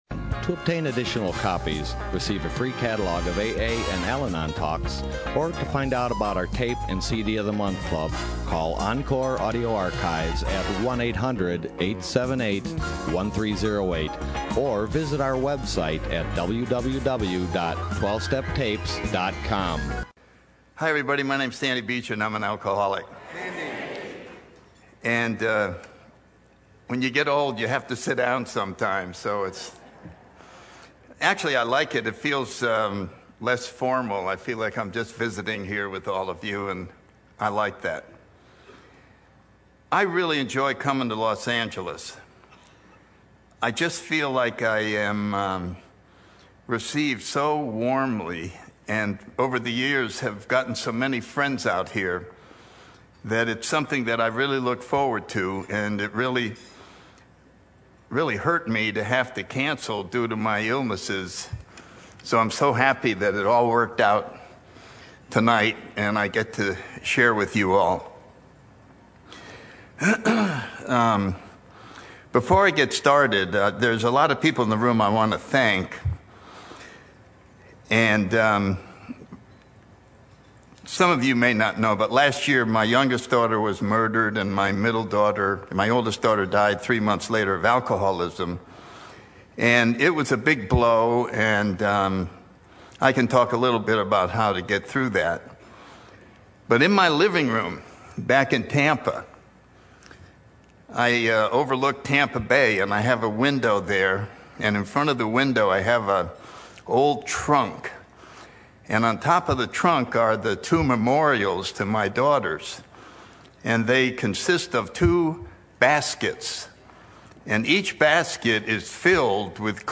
SOUTHBAY ROUNDUP 2011